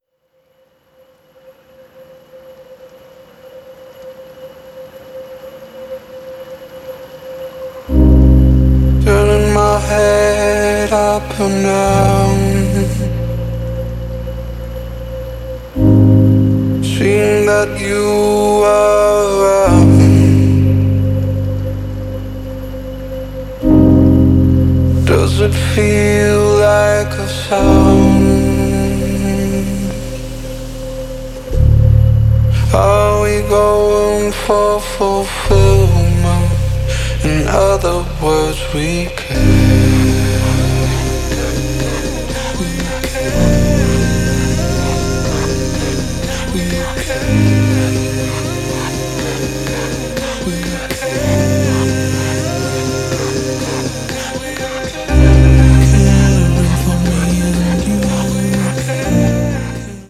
Acoustic Version